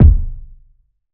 CDK Grimey Kick.wav